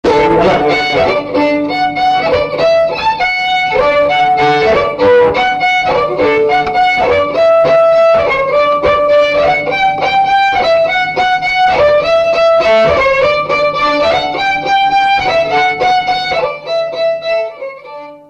Air de violon
Résumé instrumental
Pièce musicale inédite